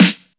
Royality free snare drum sound tuned to the G note. Loudest frequency: 3338Hz
• Tight Low End Snare Drum Sample G Key 44.wav
tight-low-end-snare-drum-sample-g-key-44-AeR.wav